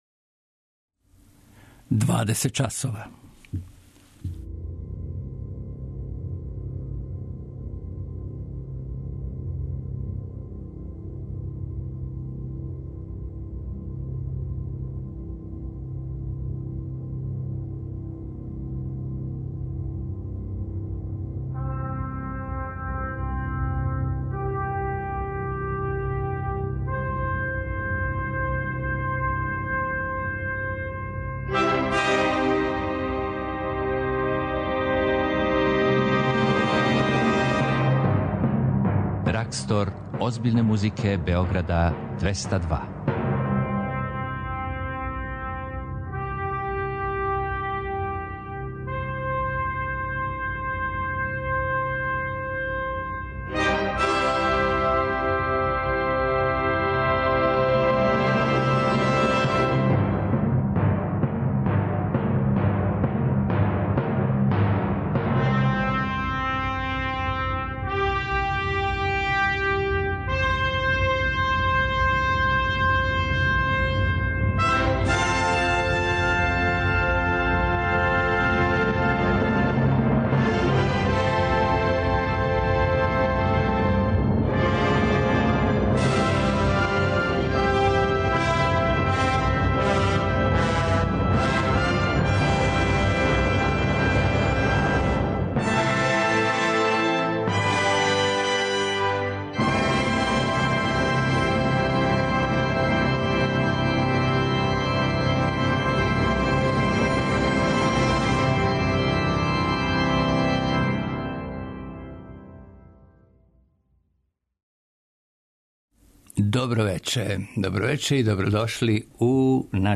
Концерт за обоу и гудаче
Концертантни став за кларинет и гудаче
Од 22ч слушамо концерт из галерије Артгет!